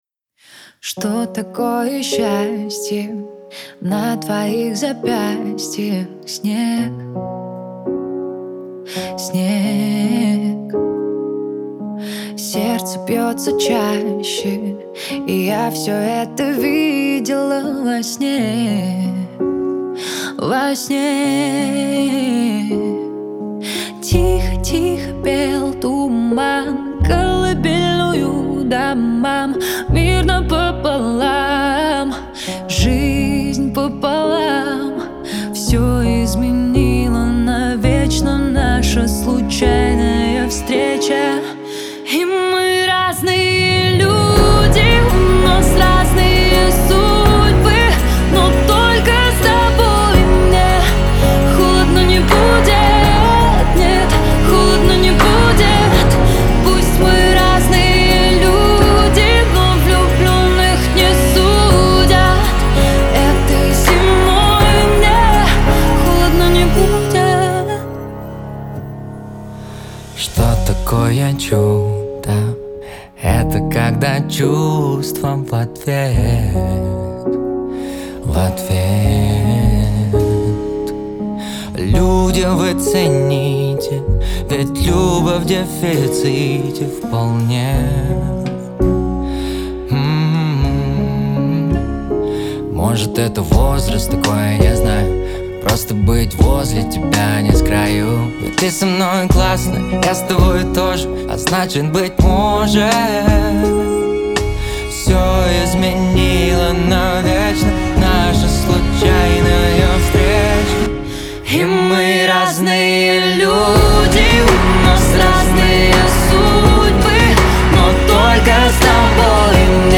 Трек размещён в разделе Русские песни.